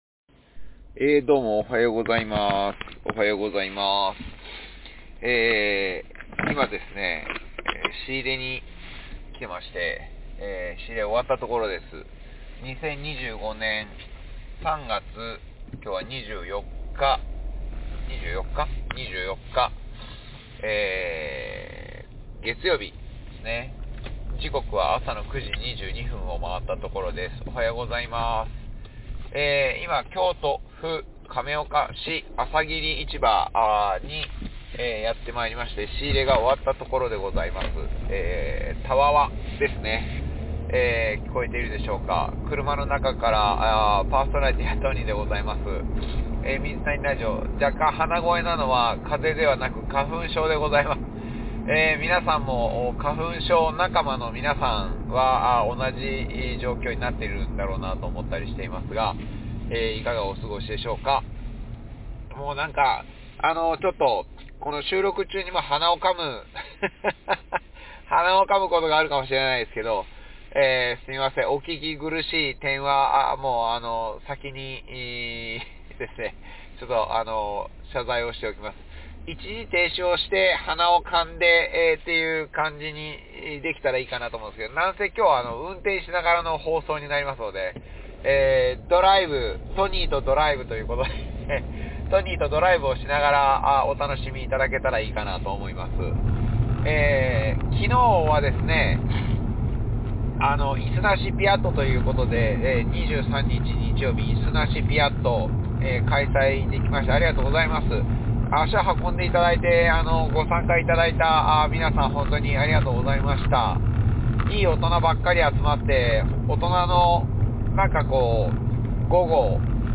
今回はクルマを運転しながらの収録です。